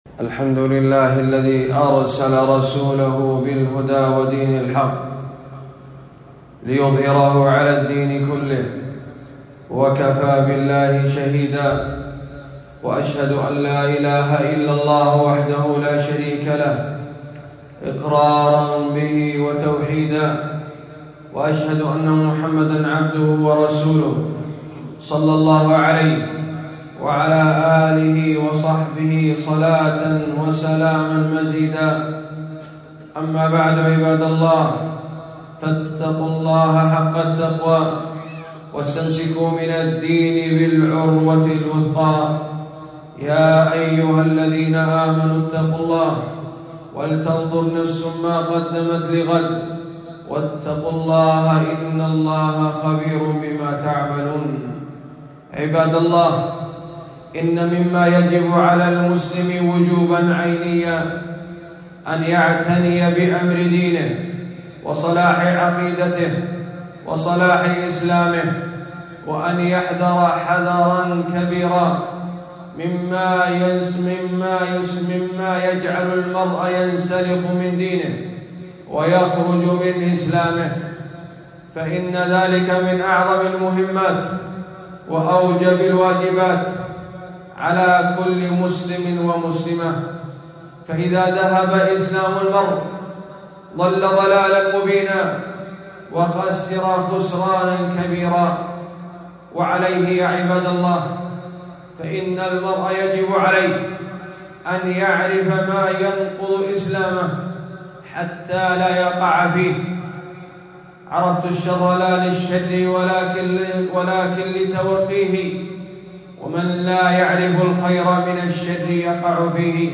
خطبة - تذكير الأنام بنواقض الإسلام